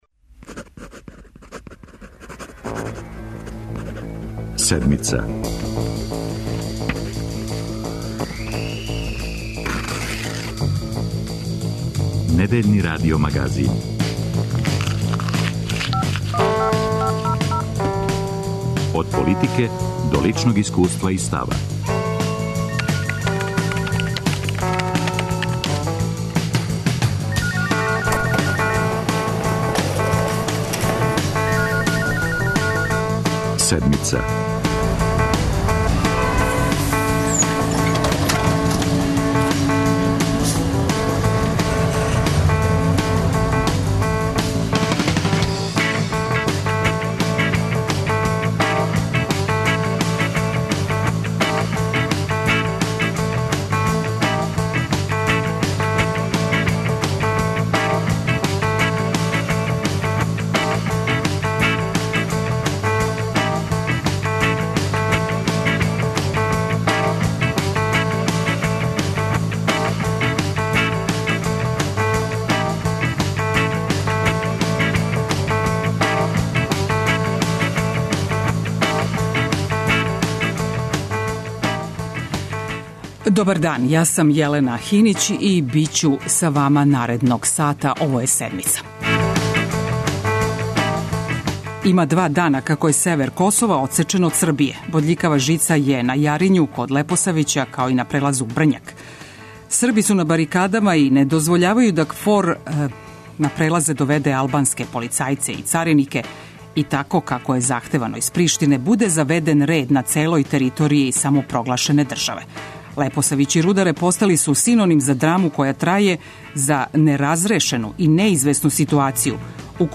Пратимо најновије догађаје на Косову и Метохији. Током емисије укључићемо наше репортере који се налазе на лицу места, а чућете и изјаве државних званичника.